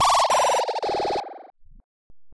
Glitch FX 39.wav